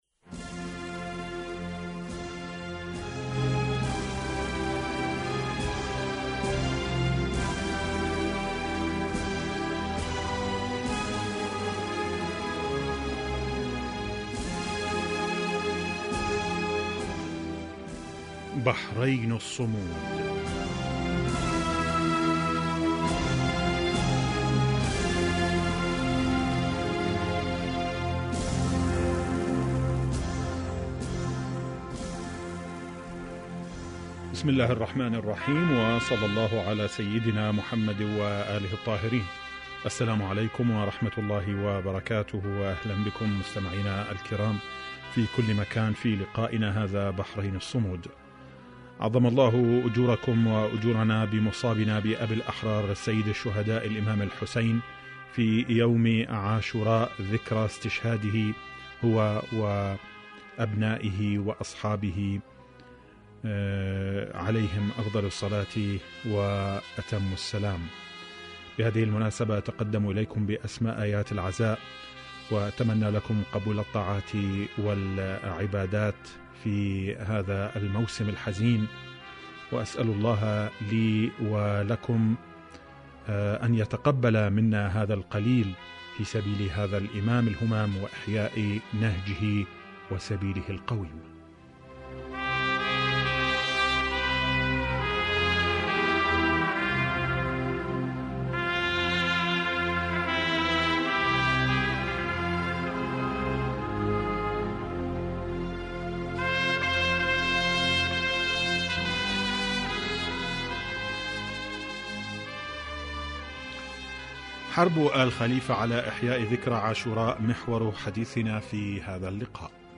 حرب آل خليفة على إحياء ذكرى عاشوراء Arabic Radio 18 views 21 August 2021 Embed likes Download إذاعة طهران-بحرين الصمود